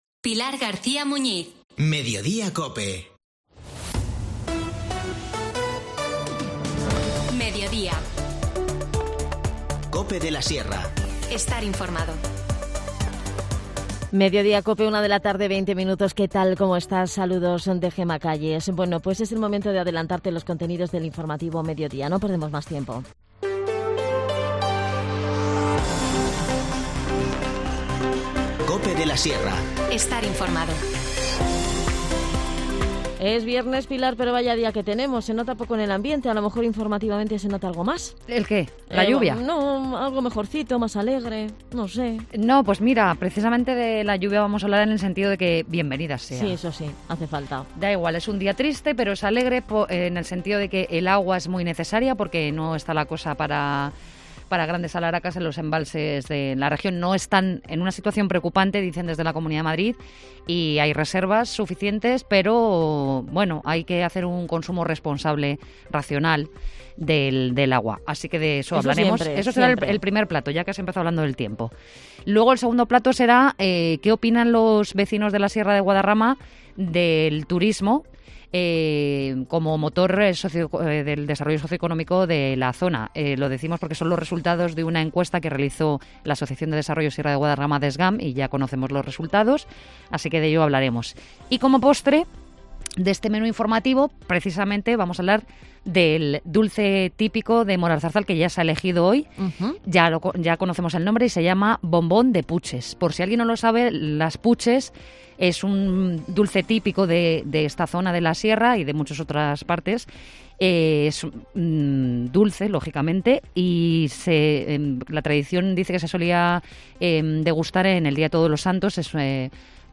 Te contamos las últimas noticias de la Sierra de Guadarrama con los mejores reportajes y los que más te interesan y las mejores entrevistas, siempre pensando en el oyente.